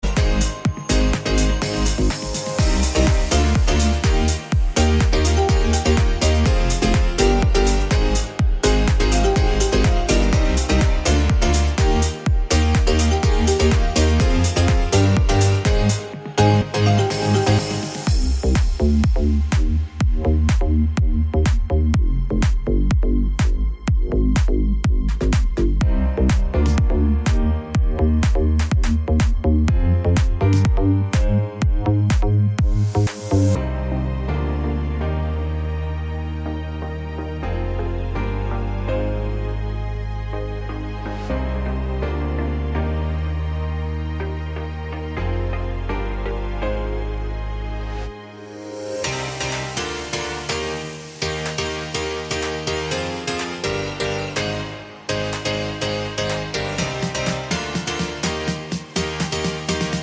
no Backing Vocals Pop (2020s) 2:44 Buy £1.50